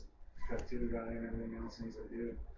That same location produced an odd audio clip captured during our walkthrough, but was not heard or reviewed until later on, and sounded as though this was a woman’s voice attempting to come through and speak to us.